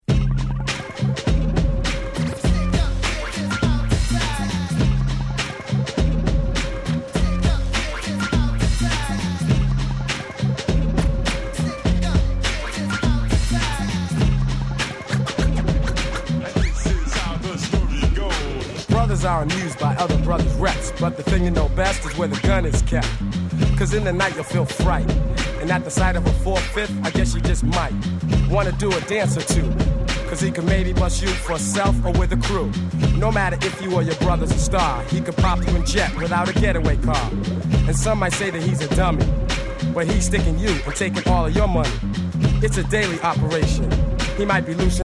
99' Street Hit Hip Hop.